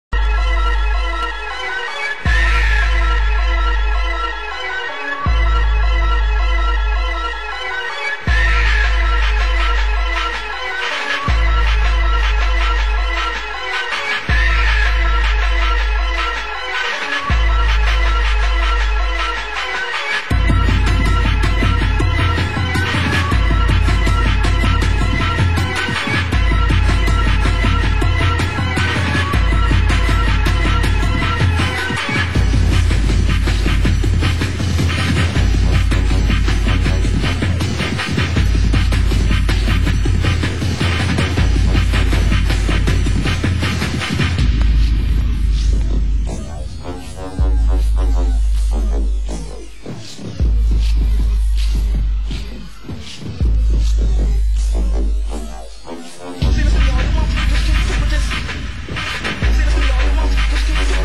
Genre: Hardcore